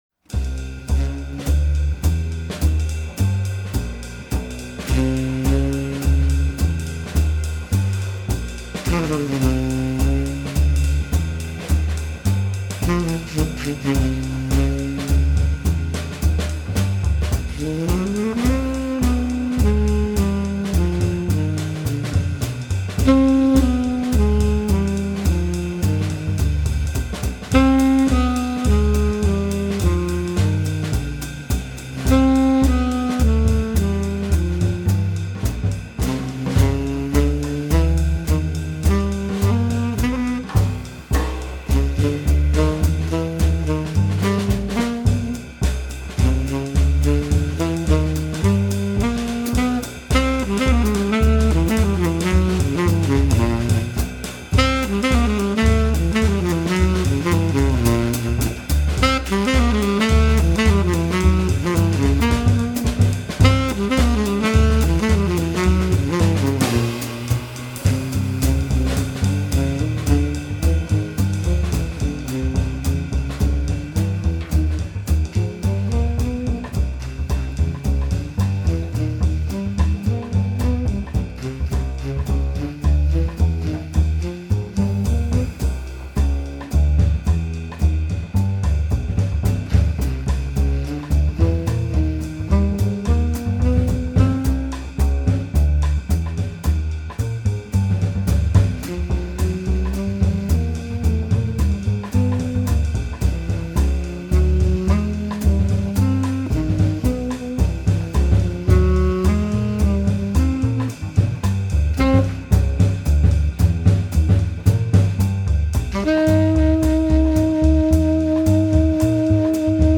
tenor saxophone
bass
drums